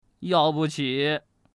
Index of /qixiGame/test/guanDan/goldGame_bak/assets/res/zhuandan/sound/woman/